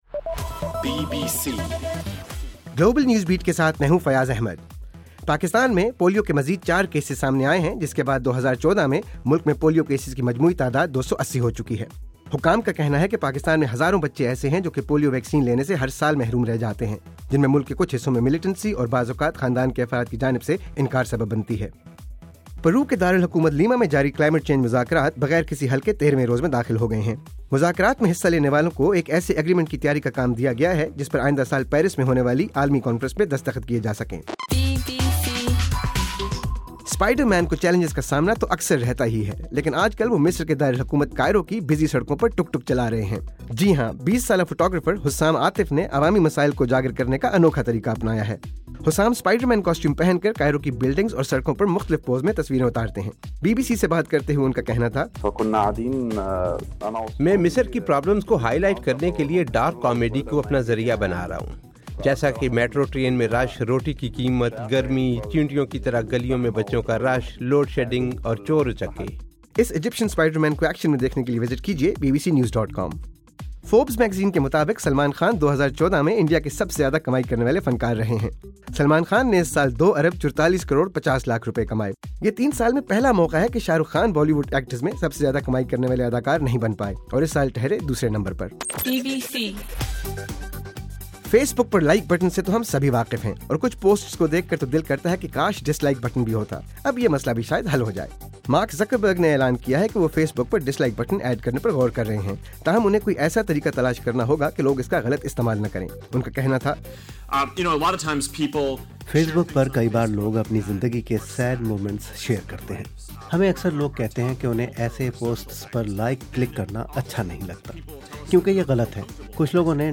دسمبر 13: رات 9 بجے کا گلوبل نیوز بیٹ بُلیٹن